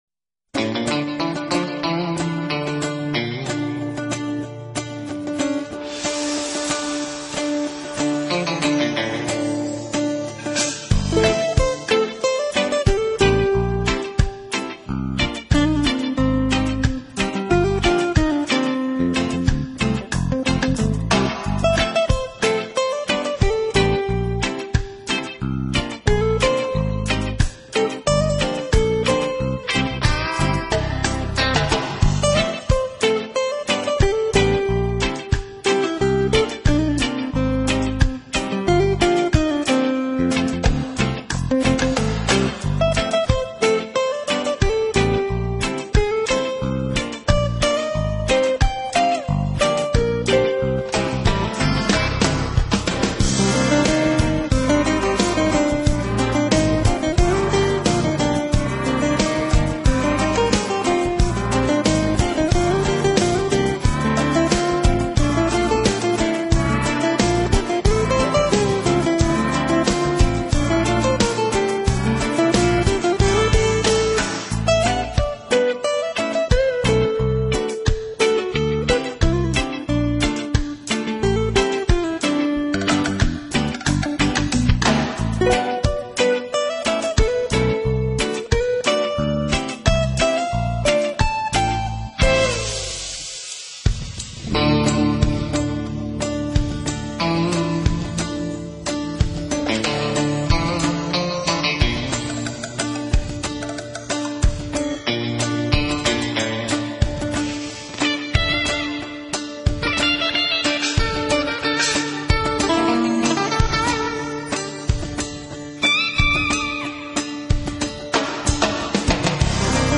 音乐类型：Jazz 爵士
音乐风格：NEWAGE，Smooth Jazz，Contemporary，Instrumental